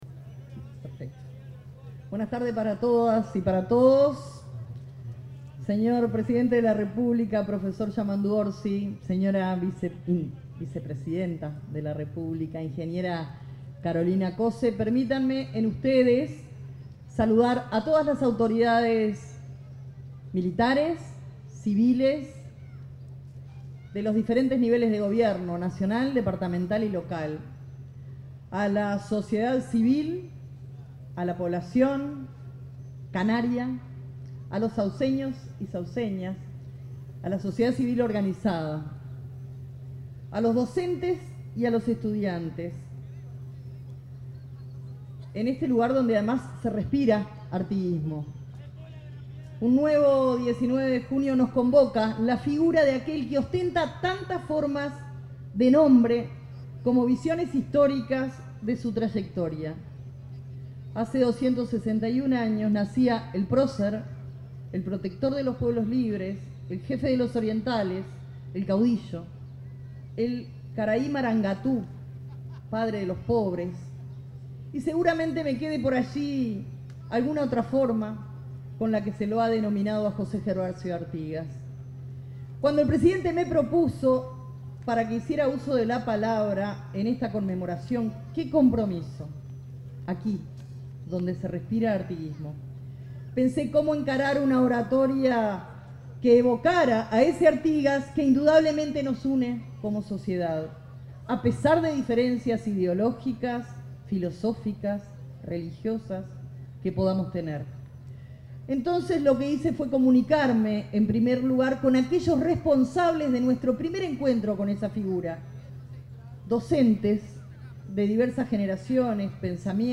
Palabras de la ministra de Defensa Nacional, Sandra Lazo
Palabras de la ministra de Defensa Nacional, Sandra Lazo 19/06/2025 Compartir Facebook X Copiar enlace WhatsApp LinkedIn Durante el acto de un nuevo aniversario del natalicio de José Artigas, la ministra de Defensa Nacional, Sandra Lazo, expresó un discurso en representación del Gobierno.